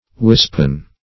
wispen - definition of wispen - synonyms, pronunciation, spelling from Free Dictionary Search Result for " wispen" : The Collaborative International Dictionary of English v.0.48: Wispen \Wisp"en\, a. Formed of a wisp, or of wisp; as, a wispen broom.